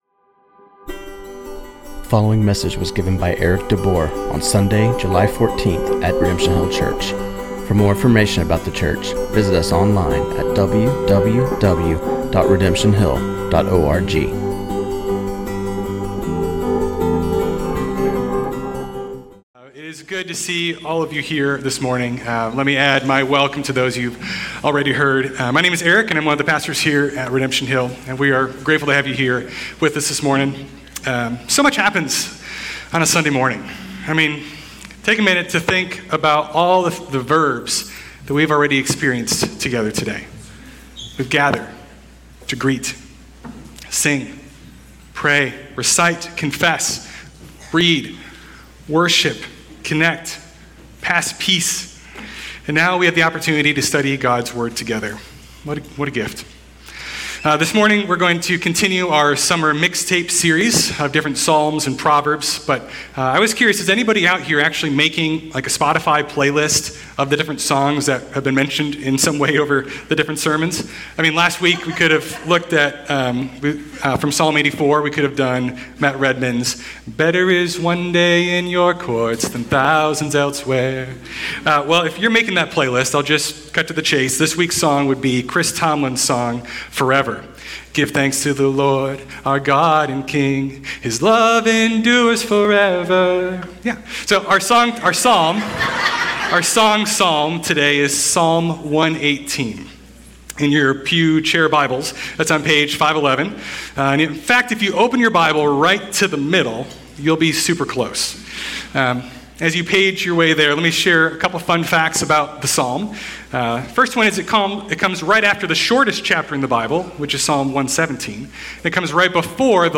This sermon on Psalm 118:1-13 was preached